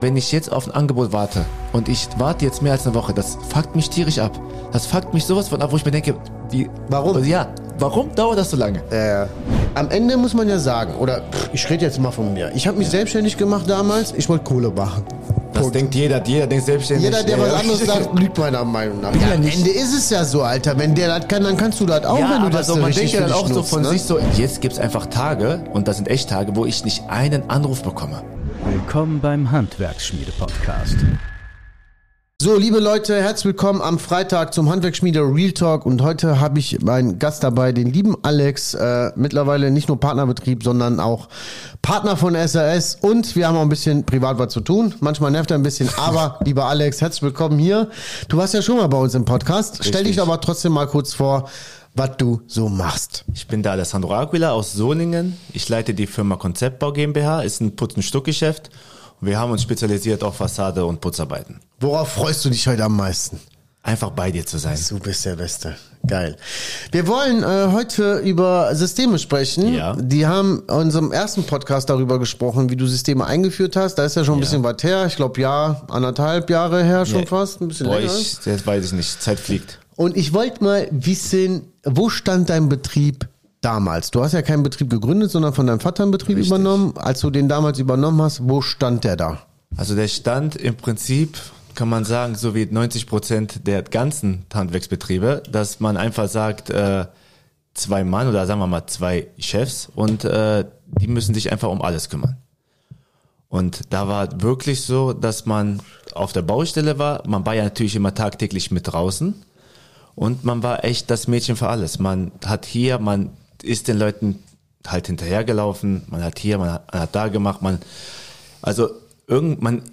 Er erzählt, wie er radikal die Baustelle verlassen hat, warum erst Chaos kam – und was danach sein ganzes Berufsleben verändert hat. Du erfährst: Wie du deinen Betrieb systematisierst, ohne die Kontrolle zu verlieren Warum du nicht alles selbst machen darfst Wie du durch Systeme mehr Freizeit und Familienzeit gewinnst Ehrlich, bodenständig und direkt aus der Praxis.